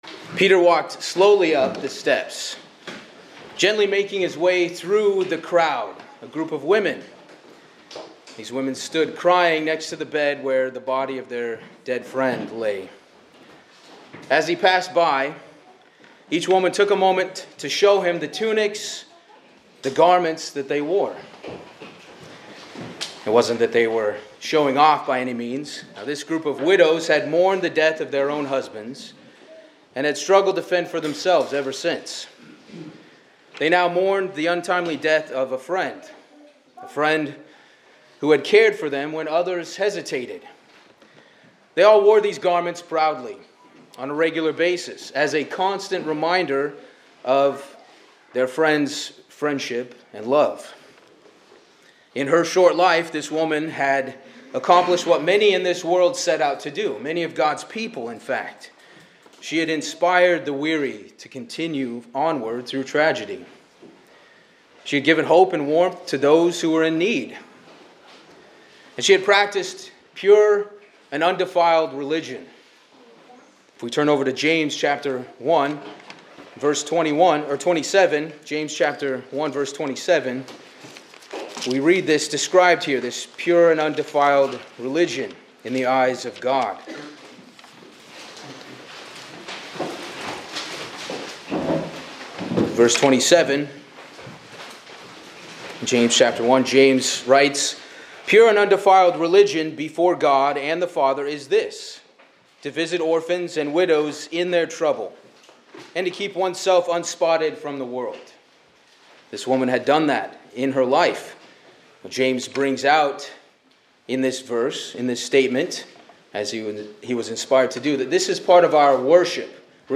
This sermon explores the theme of service within the church, using biblical examples to illustrate the value and diversity of service among believers. It encourages believers not to undervalue their contributions, whether large or small.